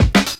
Wu-RZA-Hat 11.WAV